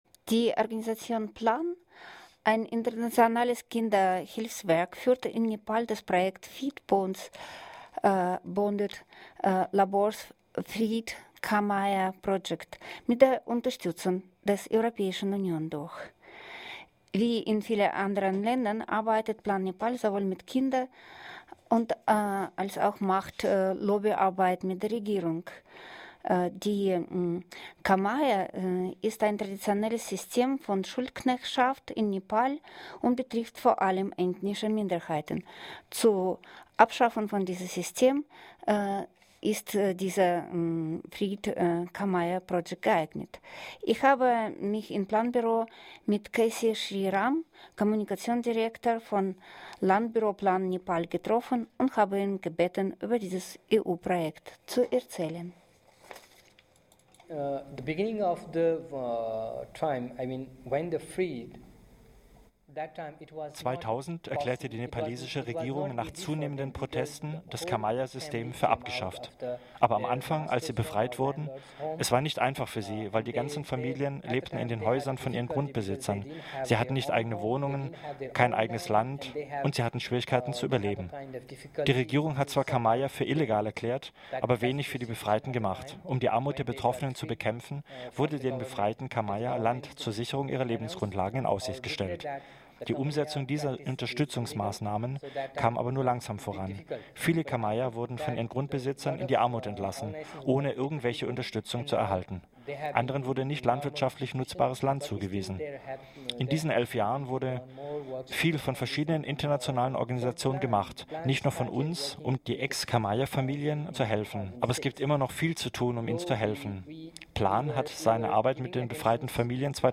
Leibeigenschaft in Nepal Interview